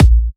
VTS1 Lovely Day Kit Drums & Perc